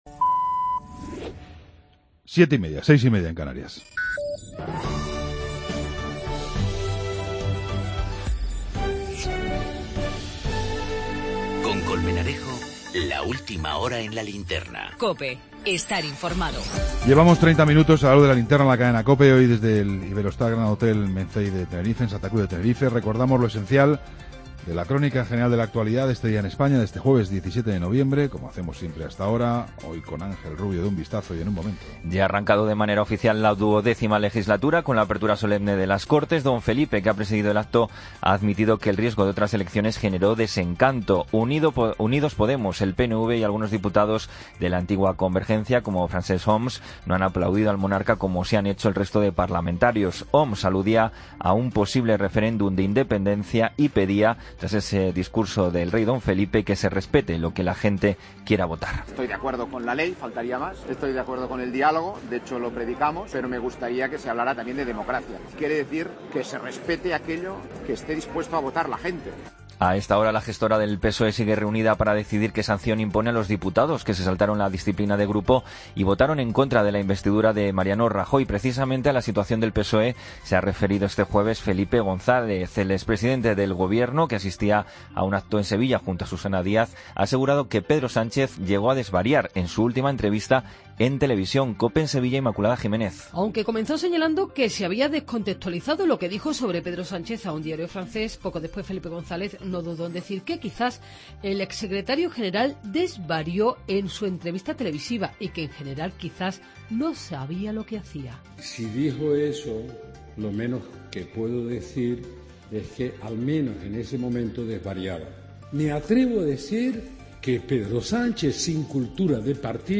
Ronda de corresponsales.
Entrevista